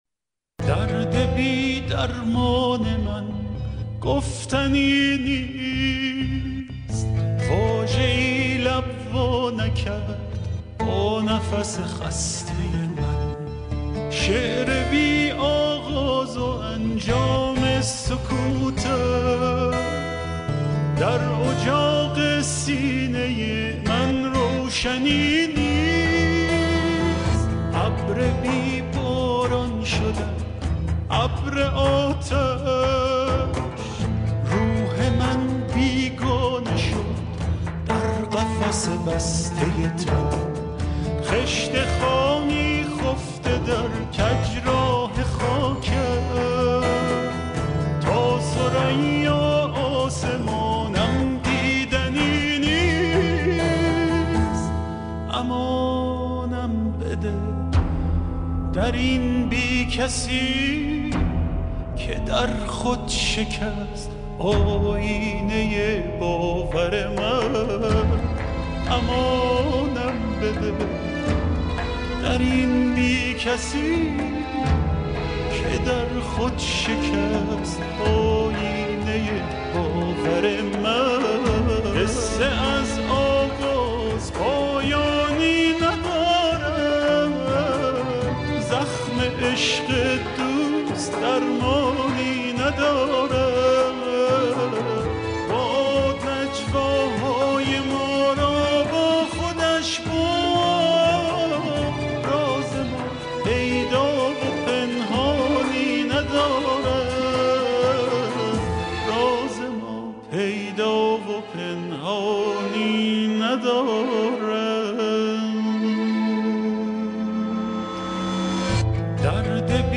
ترانه تیتراژ سریال